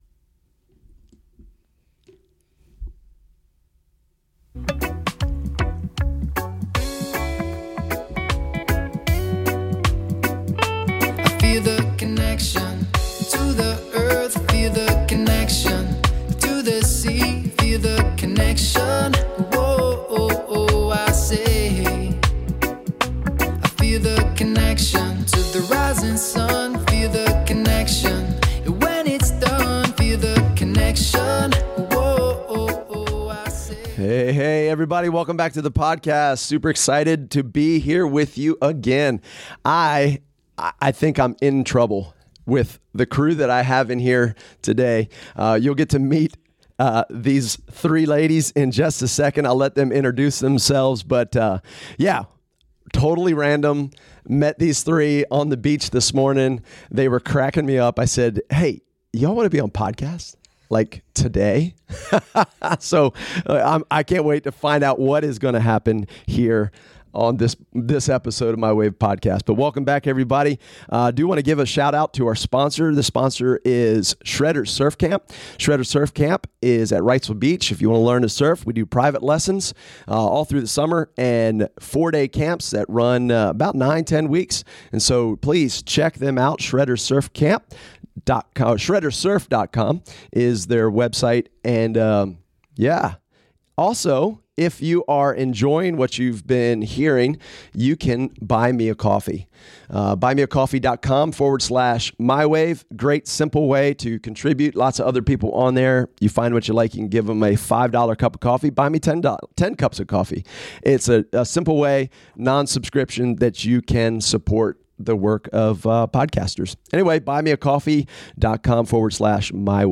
This episode is for everyone new to the sport. We hope to interview them in a year for Part 2 to see how things have progressed.